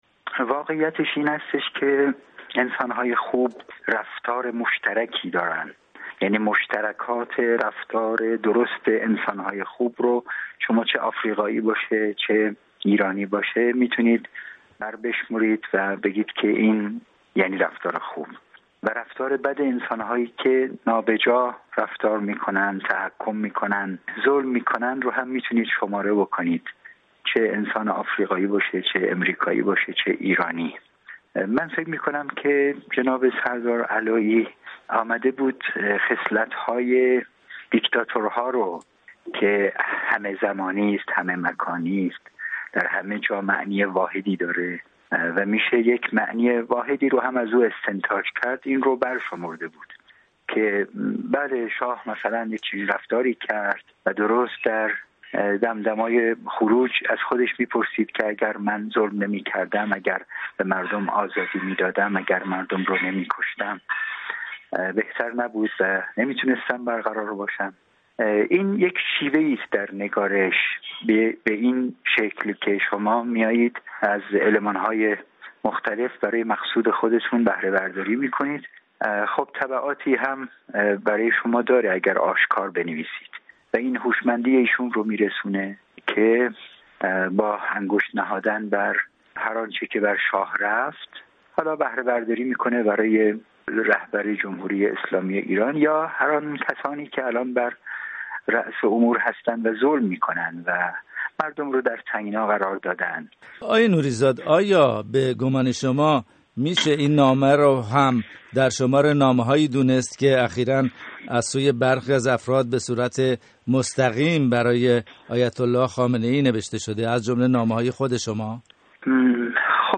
گفت و گو با محمد نوری زاد درباره یادداشت سردار پیشین سپاه، حسین علایی